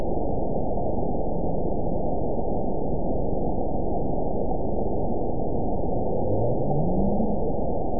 event 913723 date 04/18/22 time 15:24:42 GMT (3 years, 1 month ago) score 9.39 location TSS-AB05 detected by nrw target species NRW annotations +NRW Spectrogram: Frequency (kHz) vs. Time (s) audio not available .wav